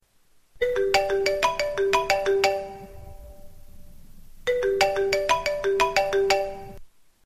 iPhone Marimba